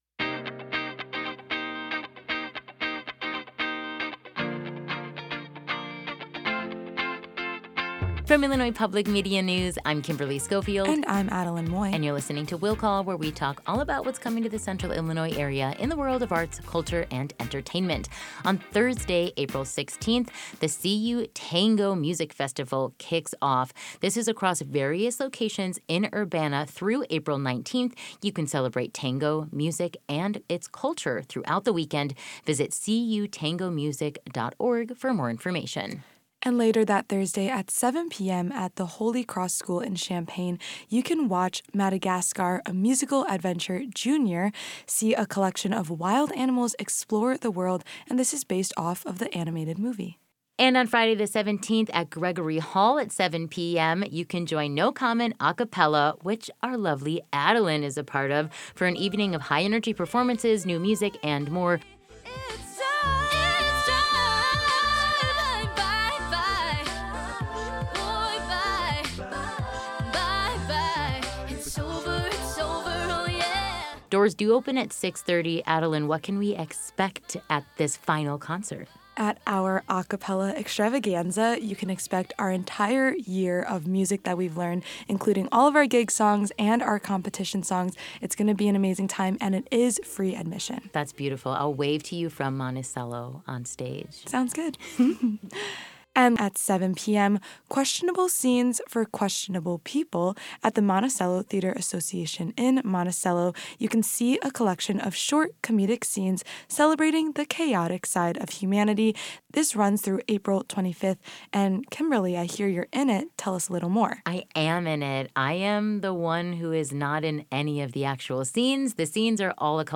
talk about weekend events on IPM News AM 580 and FM 90.9 – Wednesdays at 6:45 and 8:45 a.m., and Thursdays at 5:44 p.m.